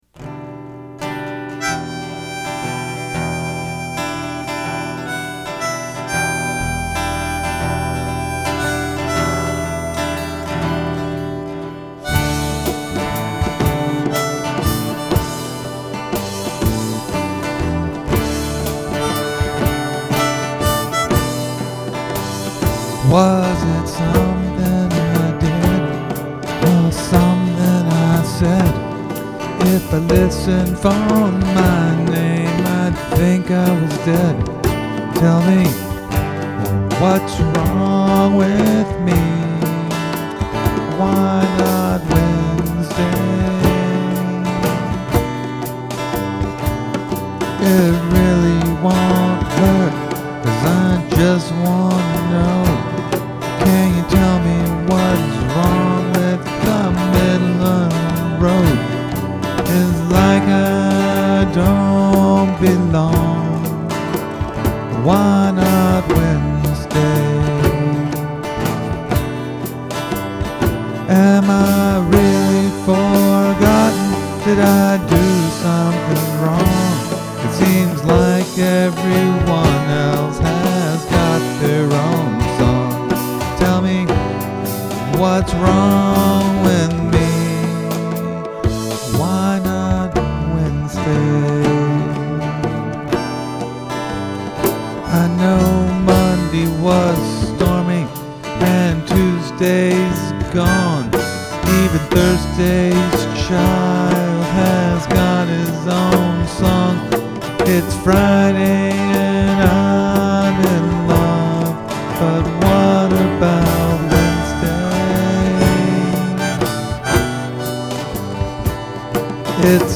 Unless otherwise noted, they're all originals, and I'm playing everything and singing.
The drums I've programmed as quickly as possible, but it's mostly a groove quantize over a couple of bass patterns.